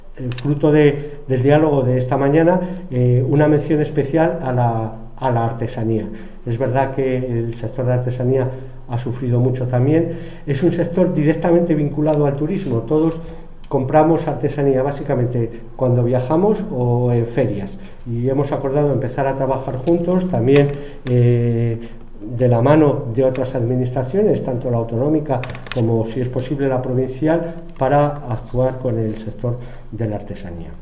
AUDIOS. Francisco Rueda, concejal de Promoción Económica y Empleo
francisco-rueda_primer-paso-ayudas-a-la-artesania.mp3